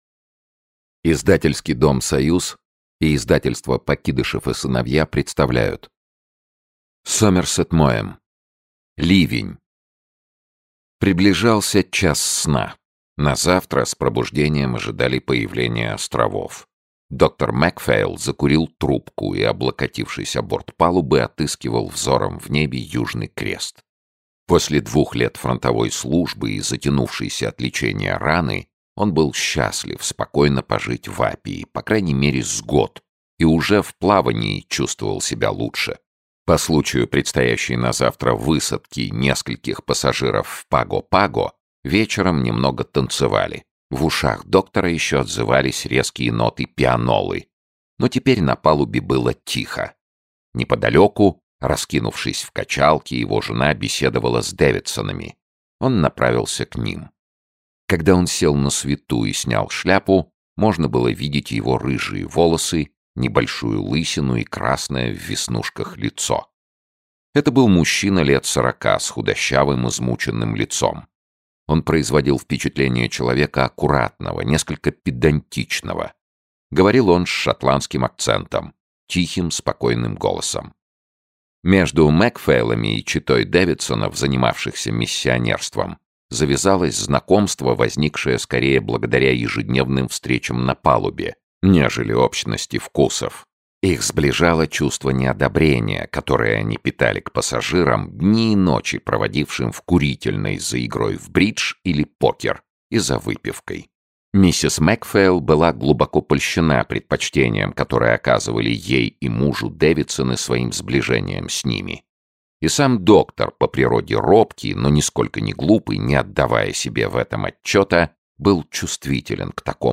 Aудиокнига Ливень Автор Уильям Сомерсет Моэм Читает аудиокнигу Сергей Чонишвили.